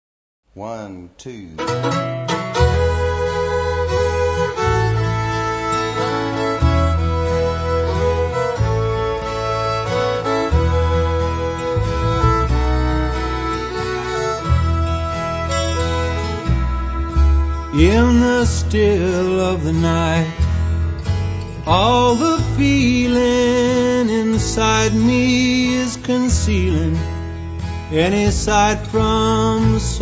chitarra, mandolino,
pianoforte
tromba
violino
• country music